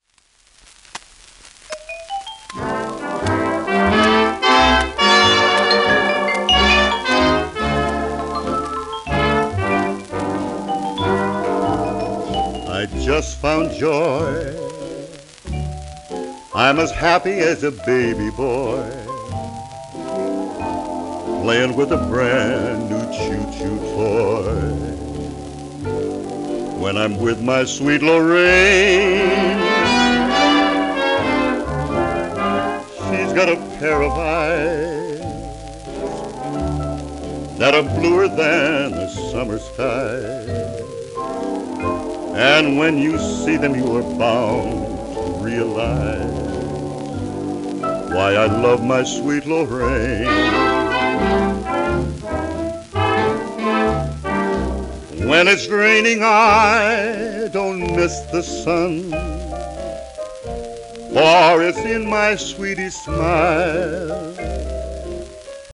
盤質A-/B+ *プレス皺、小キズ音あり
ポール・ホワイトマンやエディ・コンドンらと共にシカゴジャズを牽引した白人ヴォーカリストでバンドリーダー